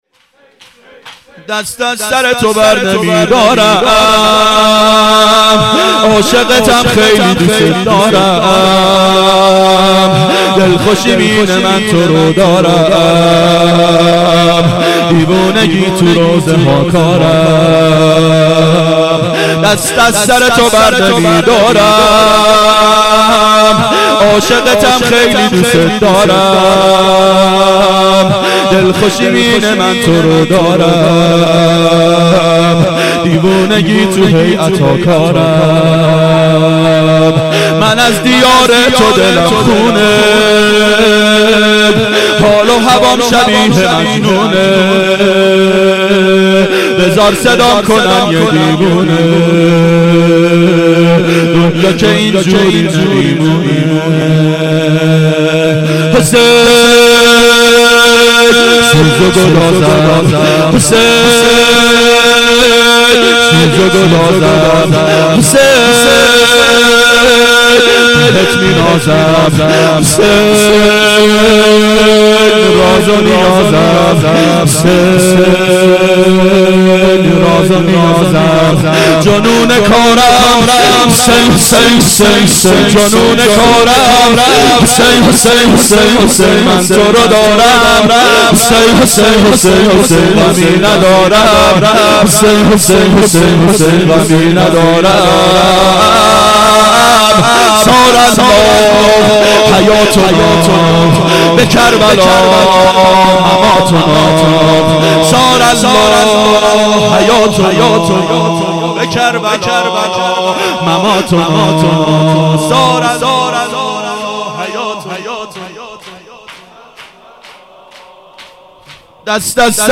ظهر اربعین 1391 هیئت شیفتگان حضرت رقیه سلام الله علیها